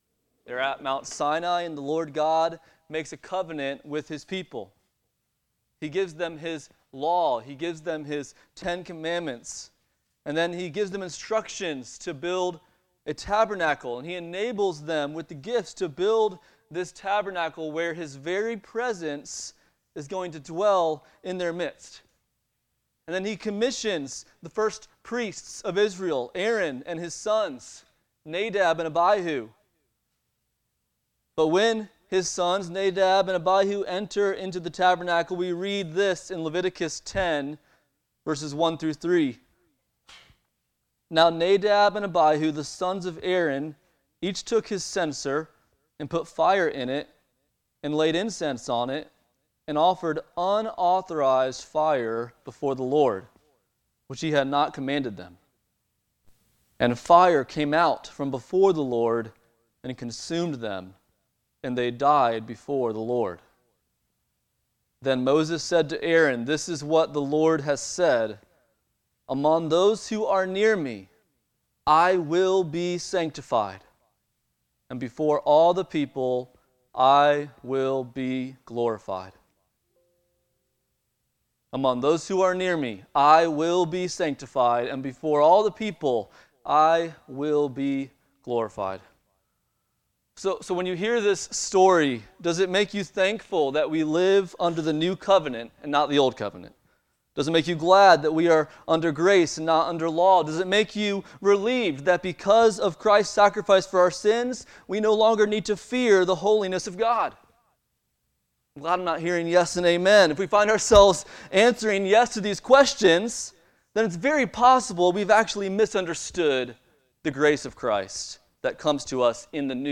Passage: Acts 4:32-5:11 Service Type: Sunday Morning